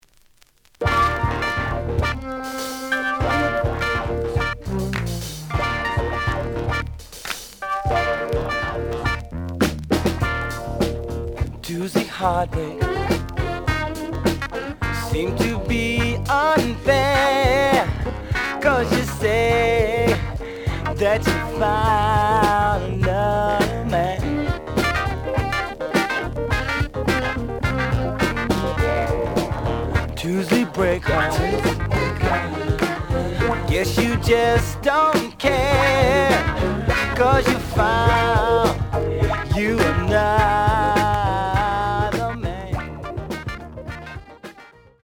The audio sample is recorded from the actual item.
●Genre: Soul, 70's Soul
B side is good shape.)